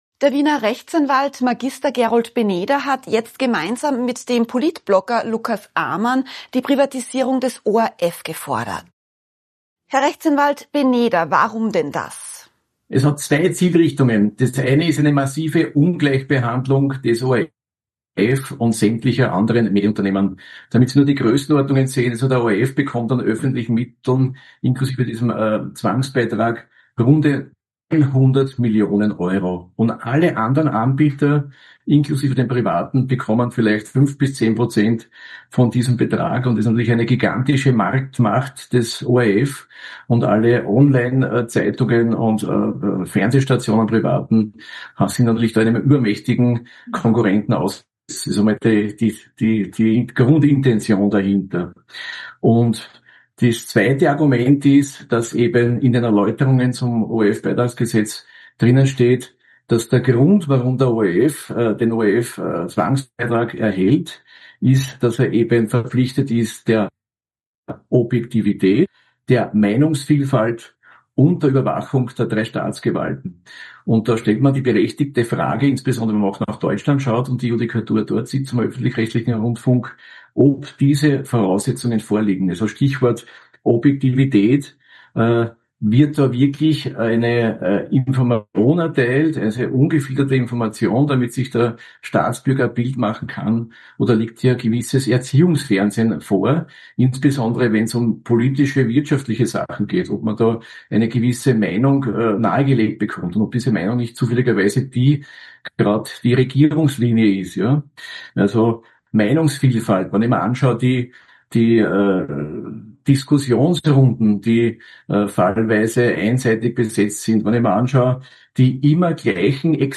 Brisantes Interview mit dem engagierten Anwalt.